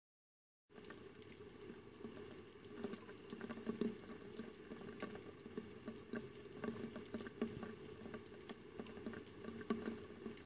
大雨
描述：倾盆大雨 用我的手机录制
Tag: 风暴 天气